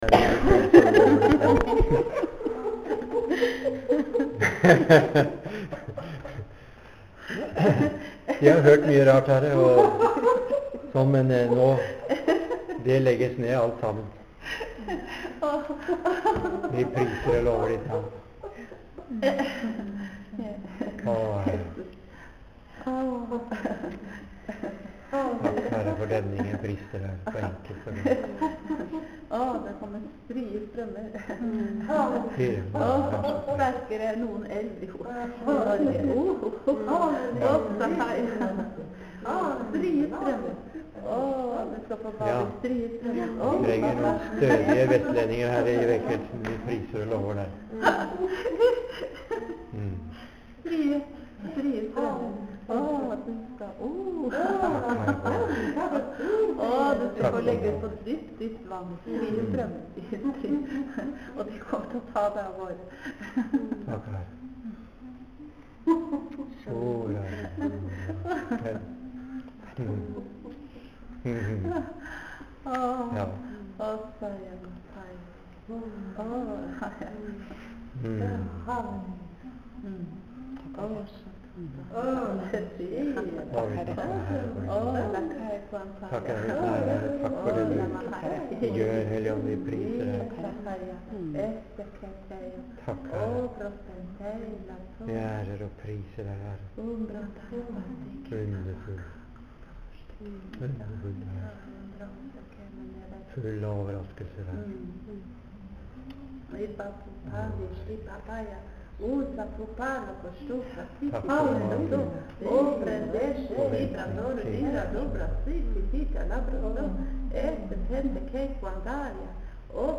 Tungetale og tyding
Høvik Misjonshus, 16.6.09.